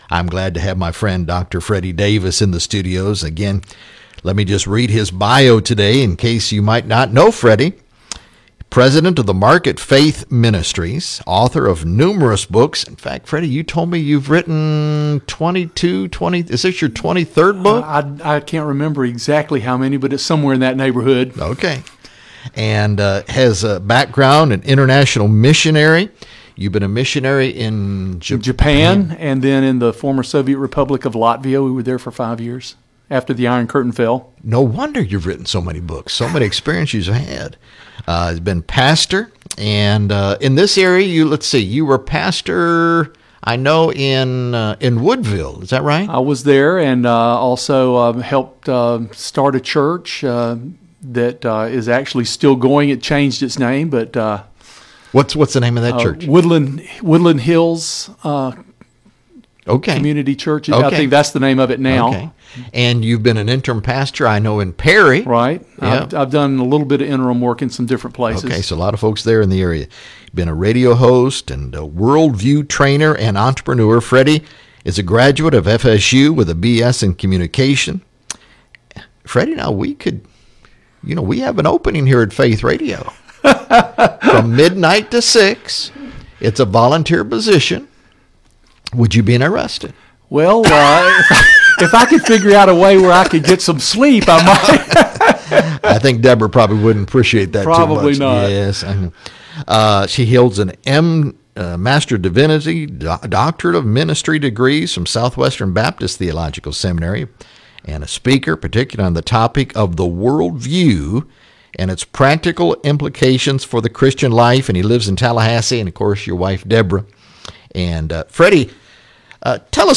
Book Interview